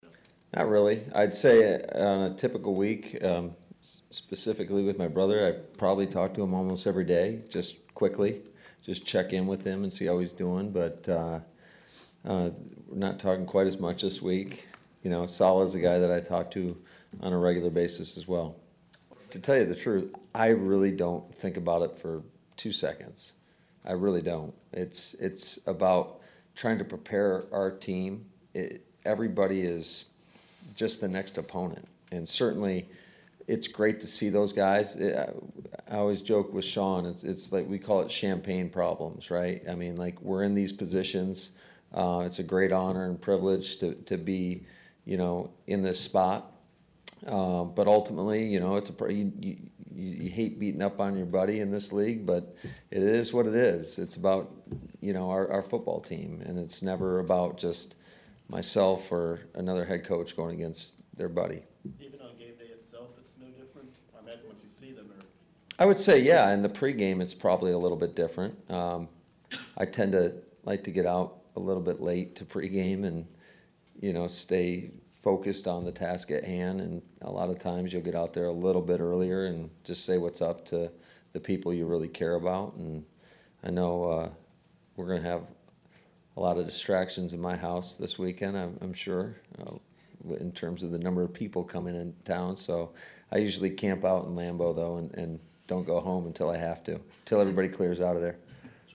LaFleur had little choice but to talk about it during his pre-practice news conference.